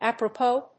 /`æprəpóʊ(米国英語), ˌæp.ɹəˈpəʊ(英国英語)/
フリガナアップラポー